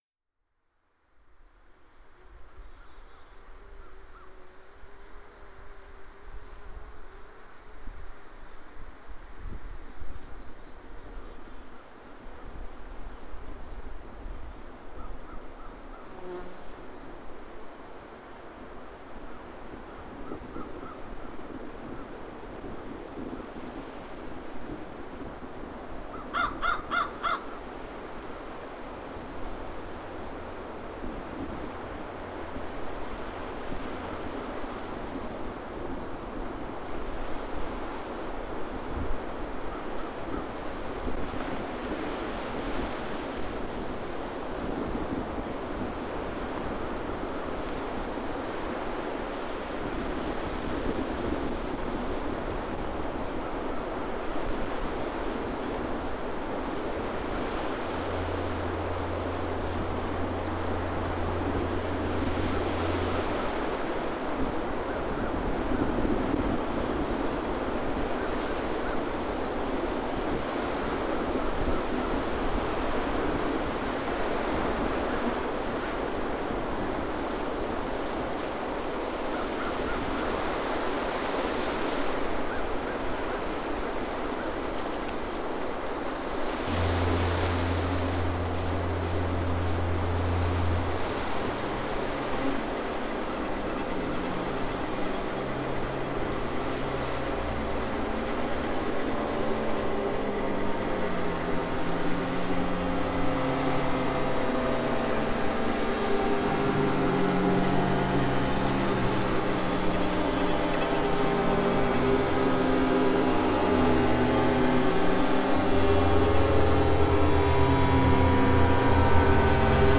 Design for installation exploring themes of conservation, transmutation and exposure in relation to the erosion of Prince Edward Island. Featuring custom built light-soundhouses, novel spatialisation, projection and sound. Proof of concept stereo demo below.